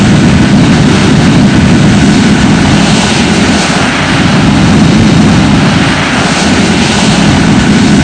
Add external IAE sounds
v2500-rumble.wav